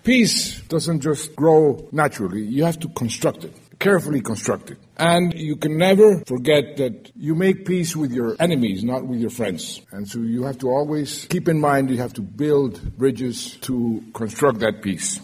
His lecture, named “From Hawk to Dove,” was delivered in front of a packed Forum Hall in the K-State Student Union and reflected on Santos’s experiences with war and peace and, at times, waging war to bring about peace.